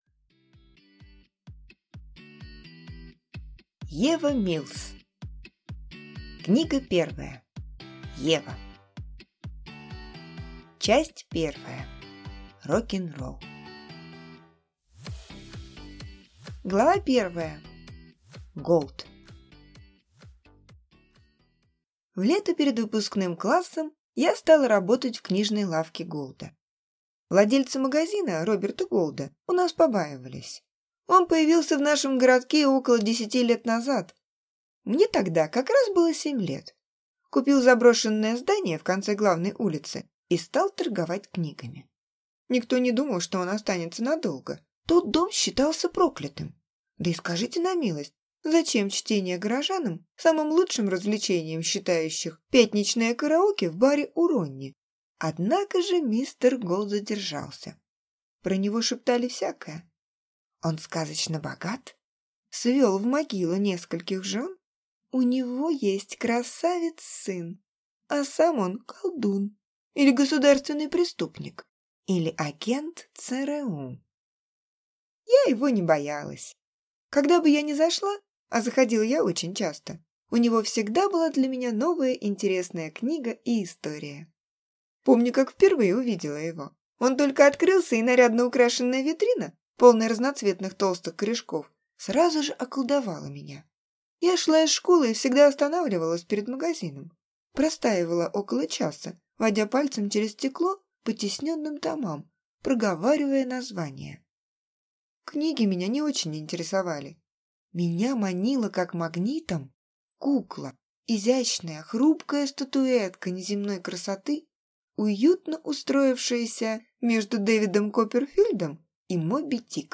Aудиокнига Ева